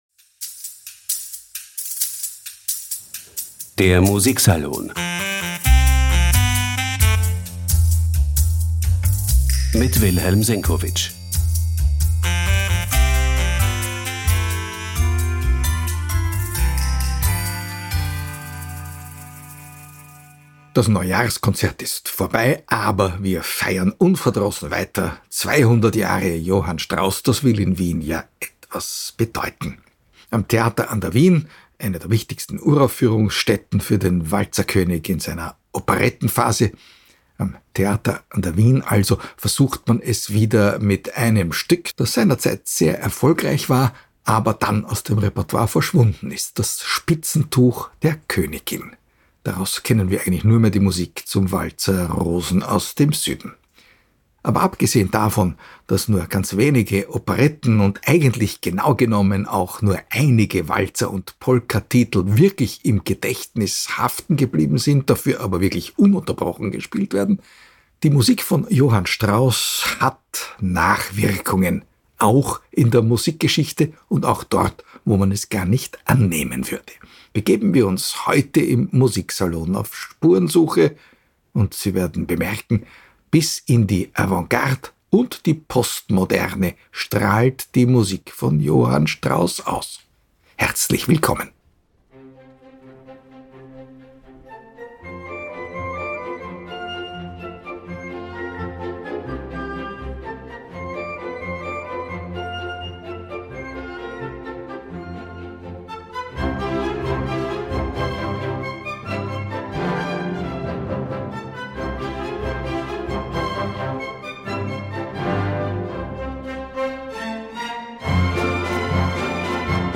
Im Musiksalon unternehmen wir heute eine akustische Spurensuche, erleben allerhand akrobatische pianistische Kunstfertigkeiten, einen Sidestep nach Hollywood, hören aber sogar einigen Anmerkungen von zeitgenössischen Komponisten, teils über der Gürtellinie, teils auch darunter.
Ein klingendes wienerisches Seelen-Pandämonium, jedenfalls, gefiltert durch Strauß’sche Walzermelodien.